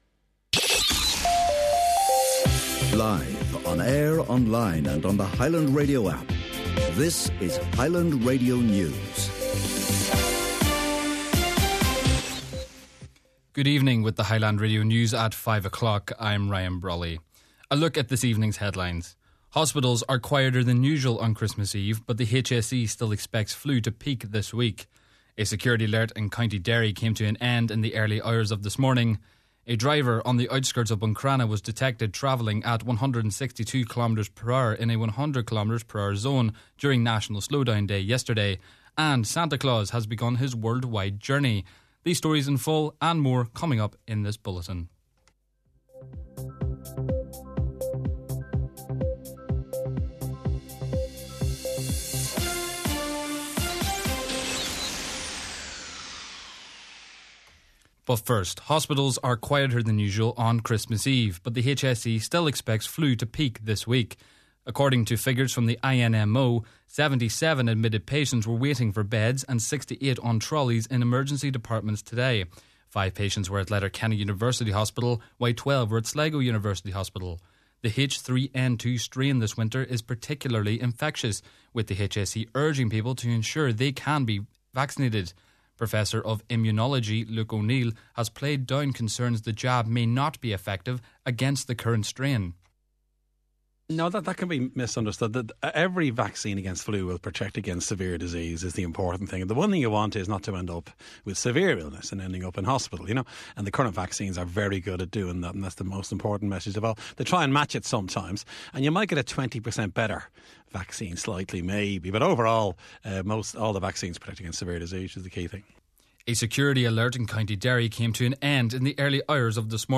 Main Evening News & Obituary Notices – Wednesday December 24th